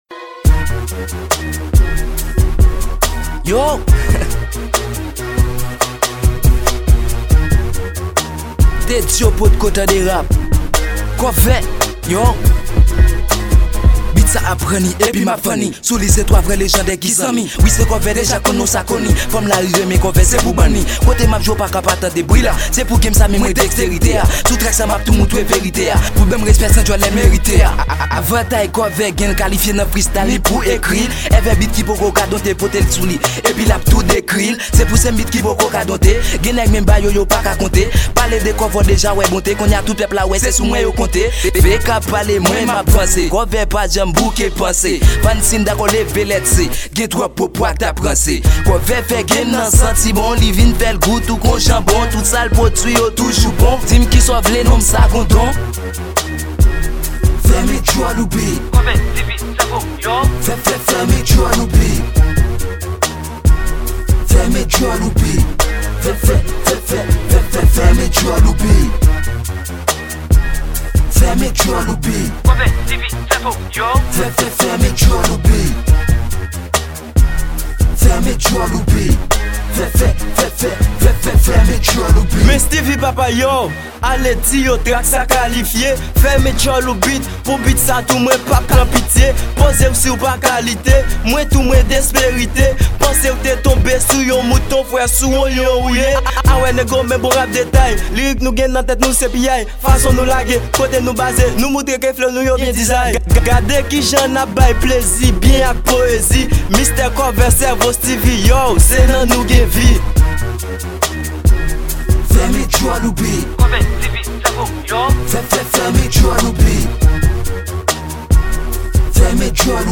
Genre: RAP.pop